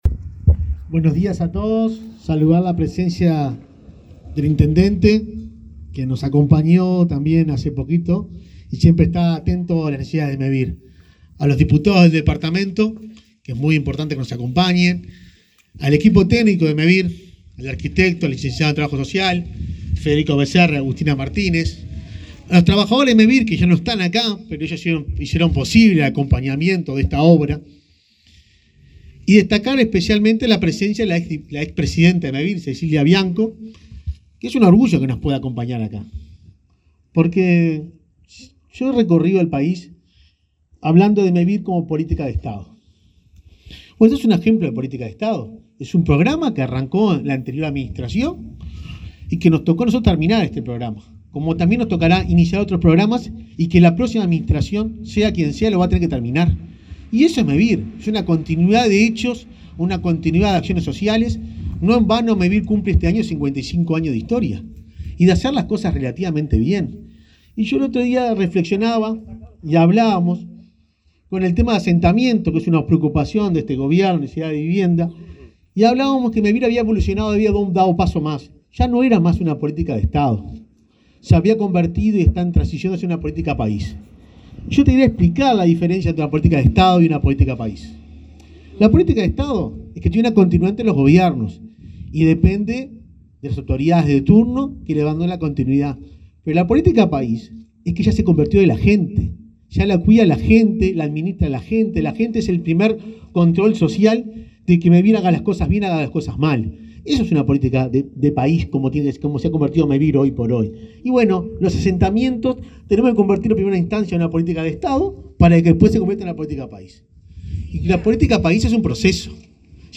Este martes 5, el presidente de Mevir, Juan Pablo Delgado, y el intendente de Lavalleja, Mario García, participaron de la inauguración de viviendas